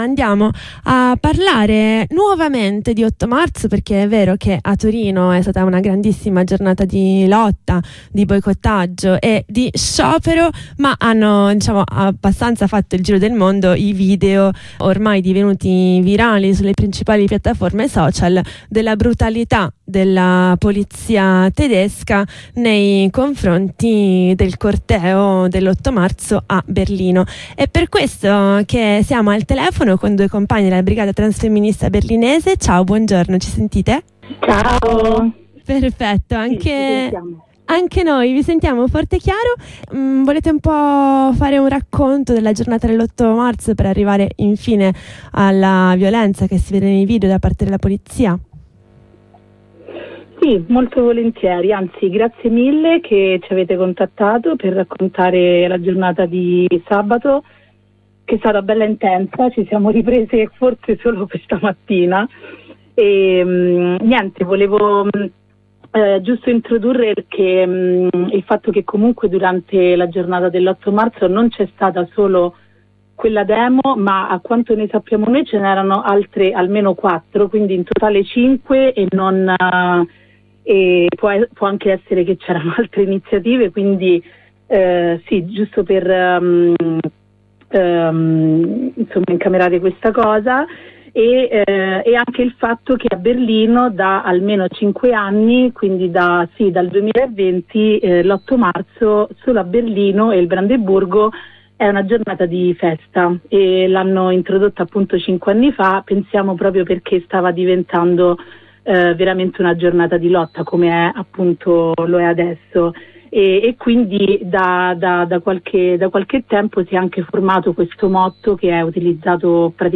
Abbiamo chiesto a due compas della Brigata Transfemminista...